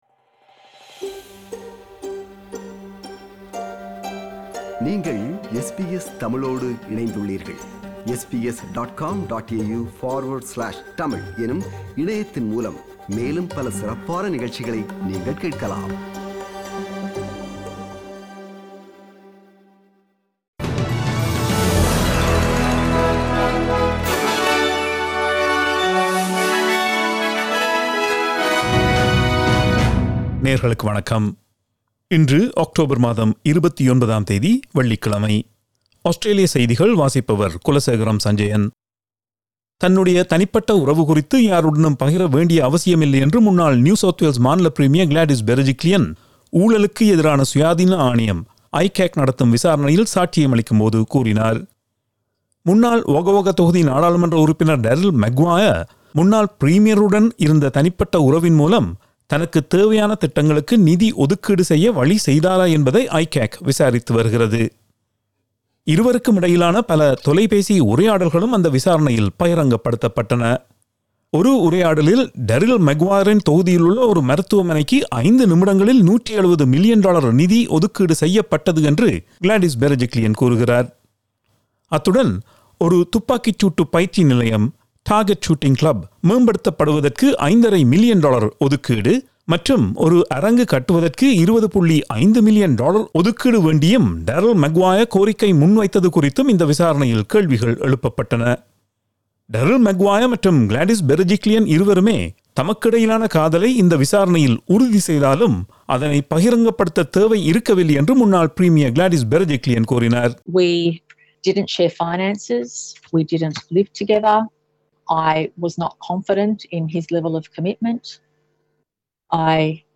Australian news bulletin for Friday 29 October 2021.